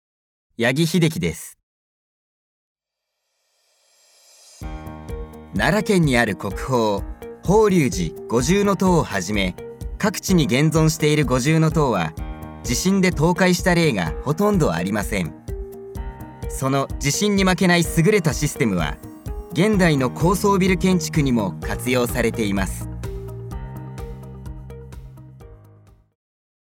ナレーション
明るいものからガッチリしたものまで作り上げます！